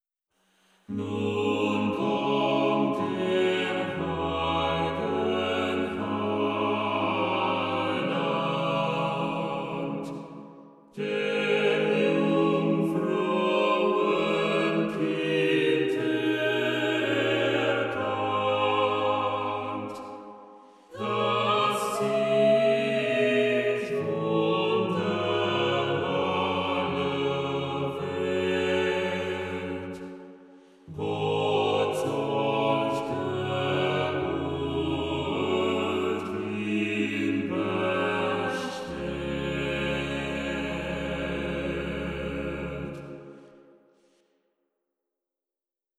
Short Lutheran chorale I made yesterday